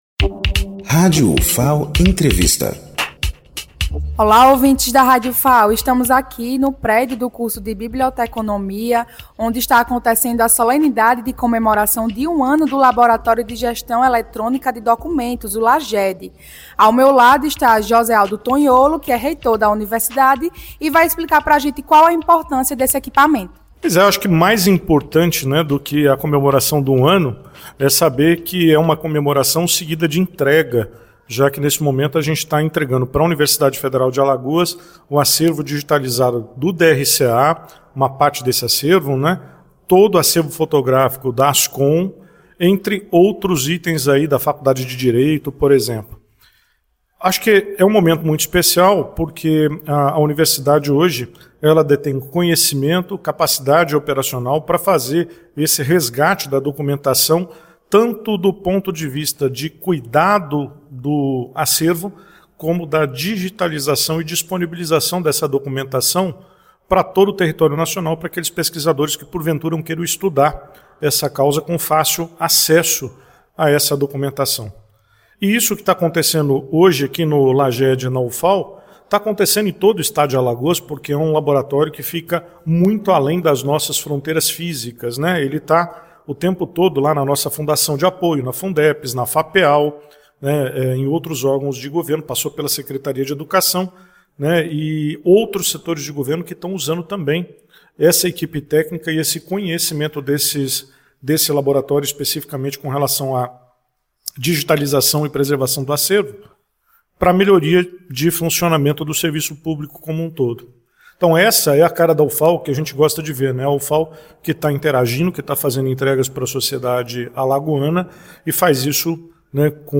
Entrevista com Josealdo Tonholo, reitor da Universidade Federal de Alagoas
Direto do prédio do curso de Biblioteconomia, a Rádio Ufal acompanha a solenidade que marca um ano de atuação do Laboratório de Gestão Eletrônica de Documentos (LAGED). A data simbólica também foi marcada pela entrega oficial do acervo imagético da memória institucional da Universidade Federal de Alagoas. Em entrevista, o reitor Josealdo Tonholo destaca a importância do material para a preservação da história e da identidade da Ufal.
josealdo_tonholo.mp3